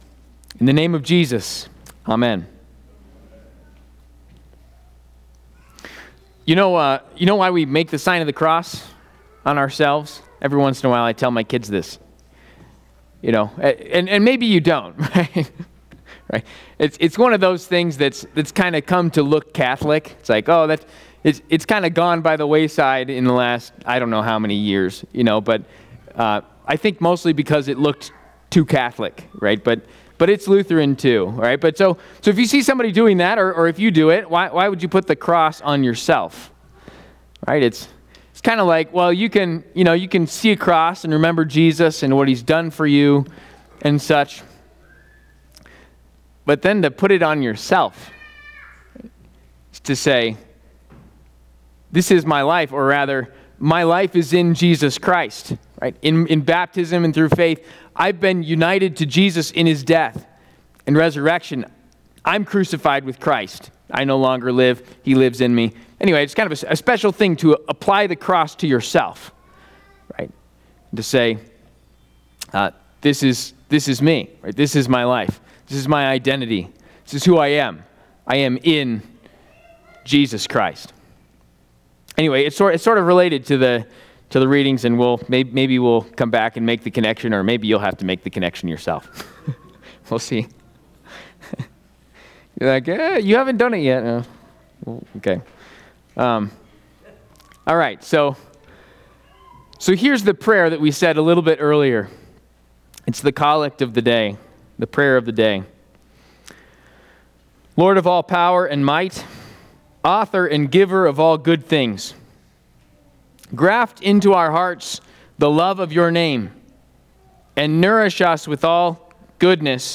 Trinity Lutheran Church, Greeley, Colorado The Love of Your Name Jun 29 2025 | 00:25:28 1x 00:00 / 00:25:28 Subscribe Share RSS Feed Share Link Embed